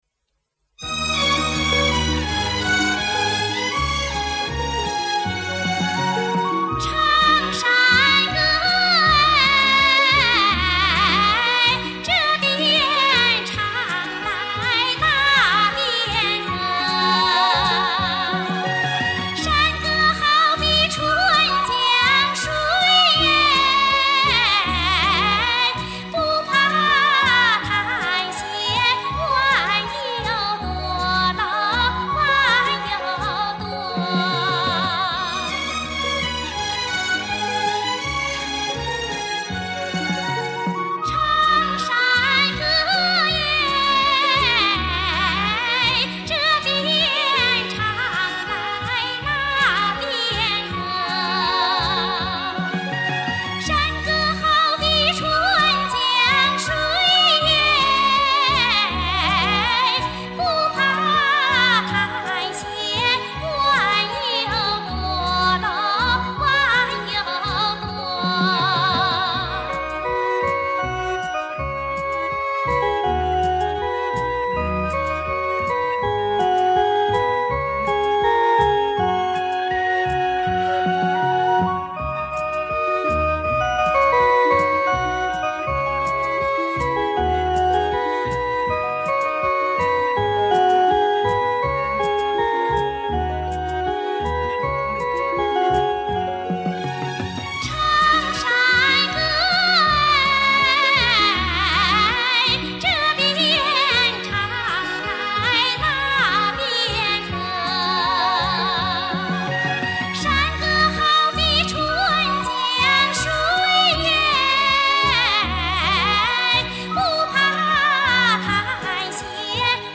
她嗓音明快脆高，音域宽广，行腔圆润婉转，舒展自若，刚柔并济，吐字清晰有力，字腔相协。